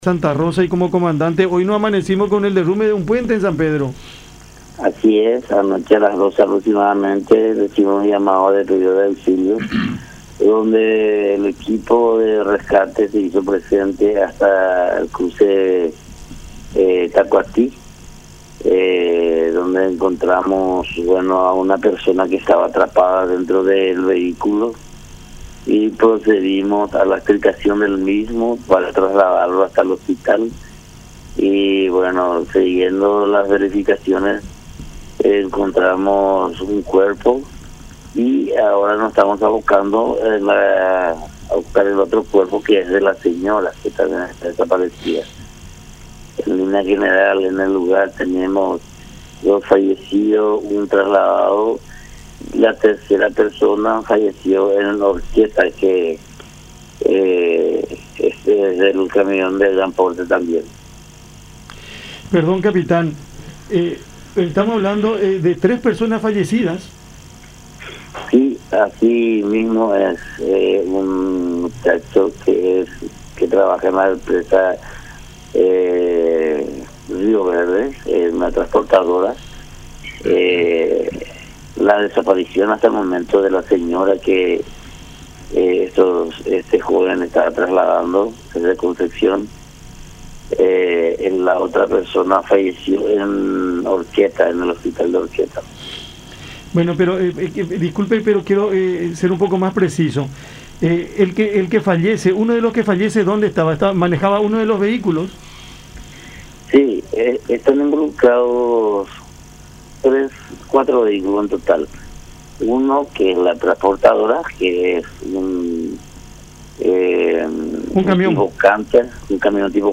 en conversación con Cada Mañana por La Unión.